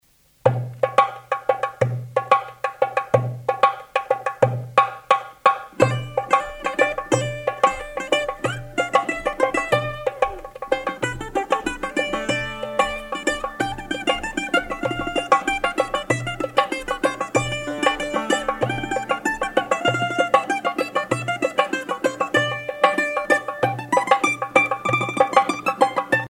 danse : syrtos (Grèce)
Pièce musicale éditée